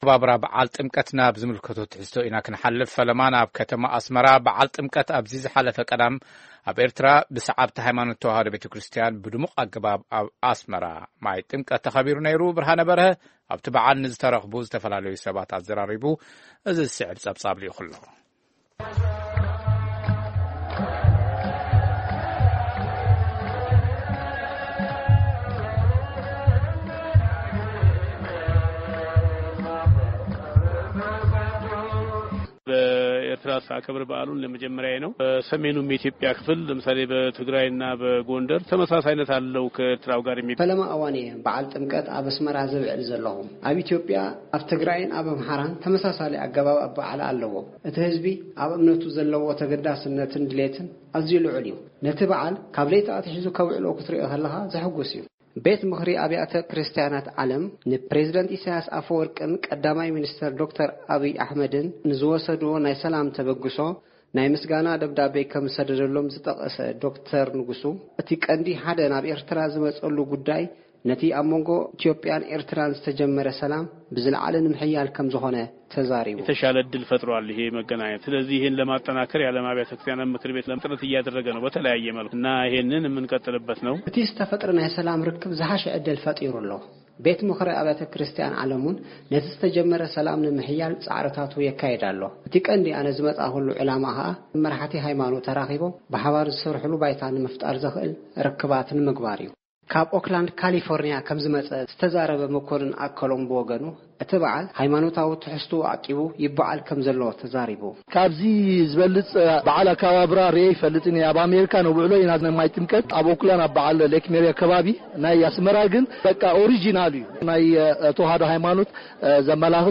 ዜና
በዓል ጥምቀት ከተማ ኣስመራ ባህላዊ መንነቱ ሓልዩ ብዝተፈላለዩ መንፈሳዊ መዛሙርን ባህላዊ ስነ ስርዓታትን ደሚቑ ተኸቢሩ።